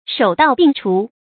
注音：ㄕㄡˇ ㄉㄠˋ ㄅㄧㄥˋ ㄔㄨˊ